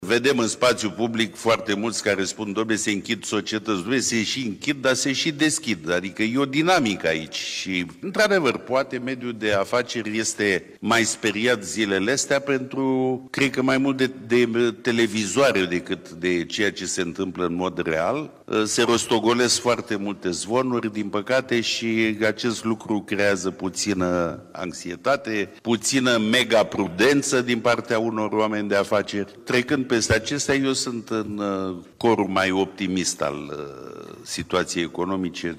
Declarațiile au fost făcute în cadrul unei conferințe organizate de Camera de Comerț și Industrie pe tema reciclării.